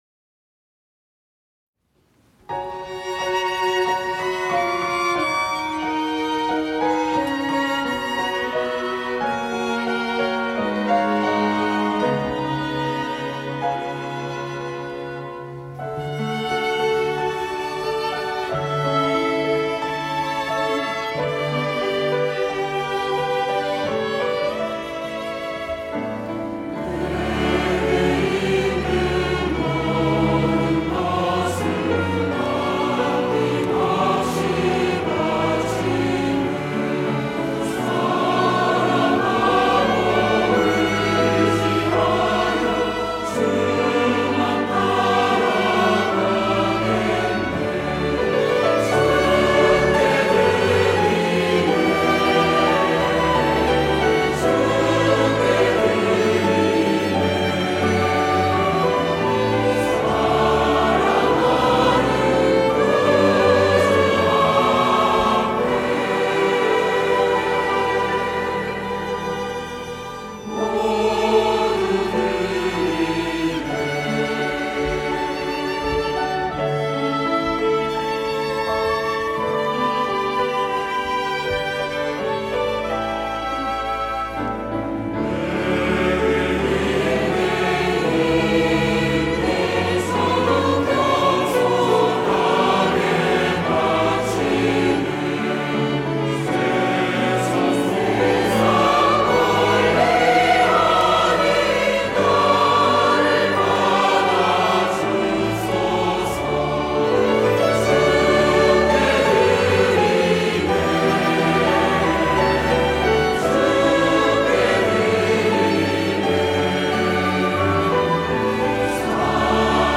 호산나(주일3부) - 내게 있는 모든 것
찬양대